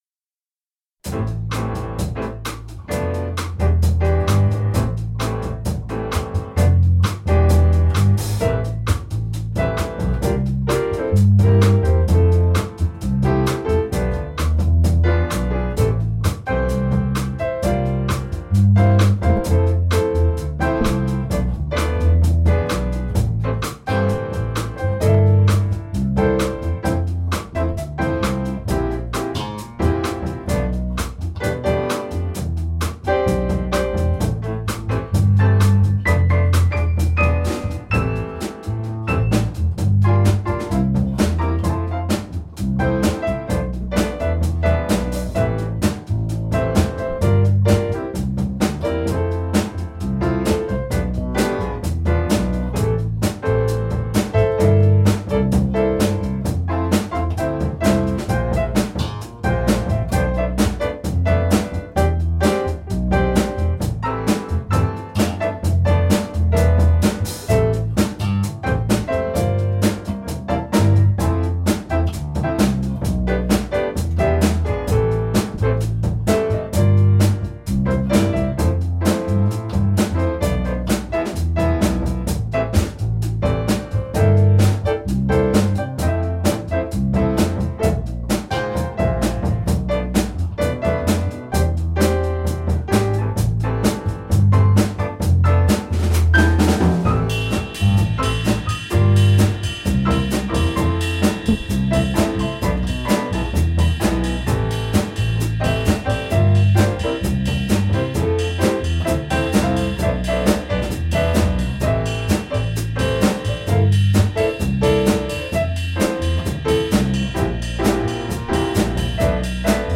Exemples de play-backs (versions de démonstration) :
Tempo 130 – Intro, 5 refrains, 6 « I love you »